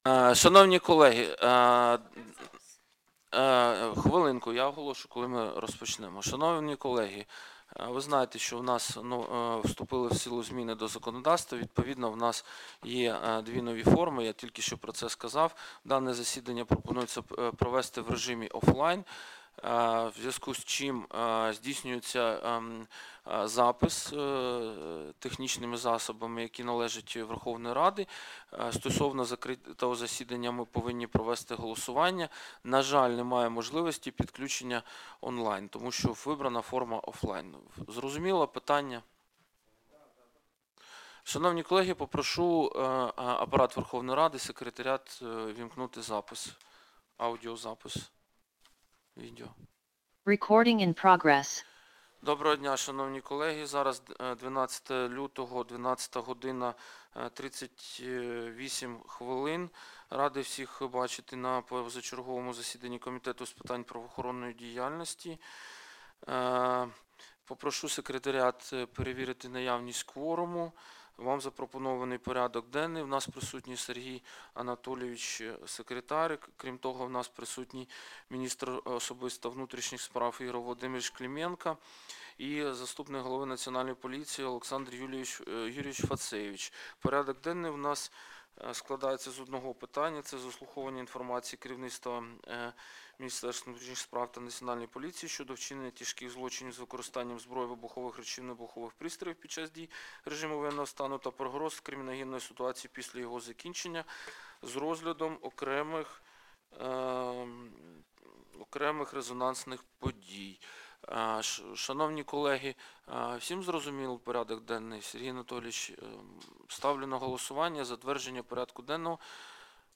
Аудіозаписи засідань Комітету за лютий 2026 року